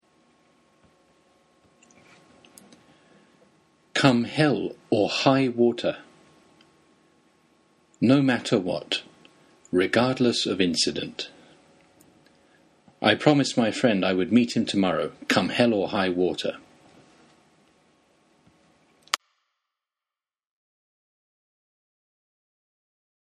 日本語では「たとえ火の中水の中」「雨が降ろうと槍が降ろうと」に当たります。 英語ネイティブによる発音は下記のリンクをクリックしてください。